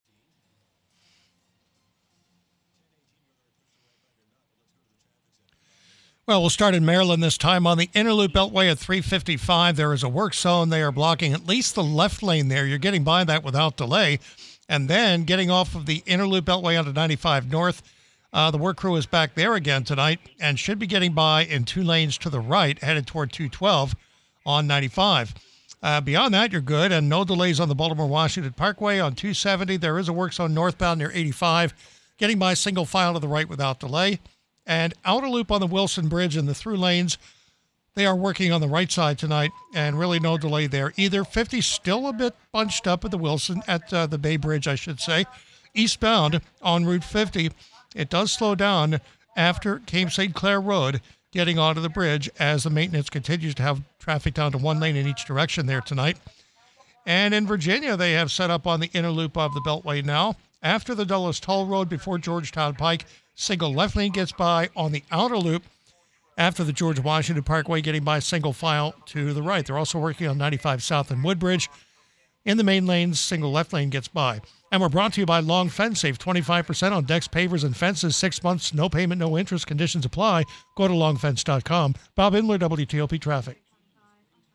Recent Traffic Report.